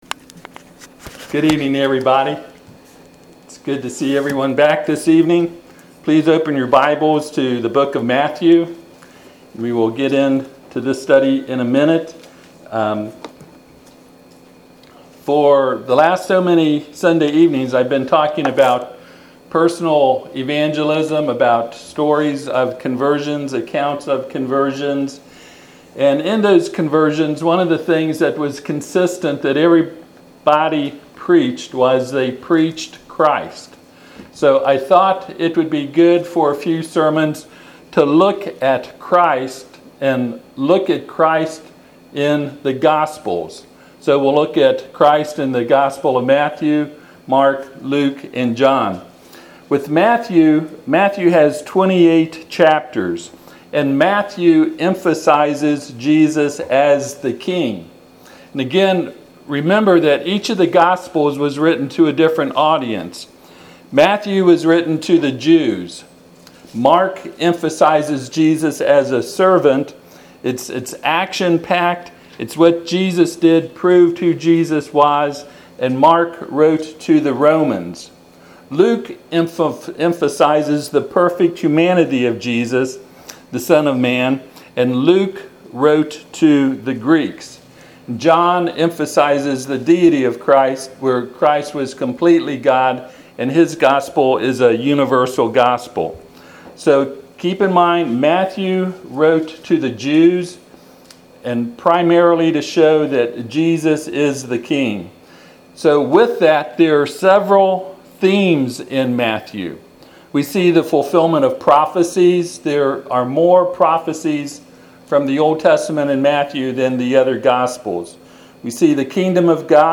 Matthew 1-28 Service Type: Sunday PM Topics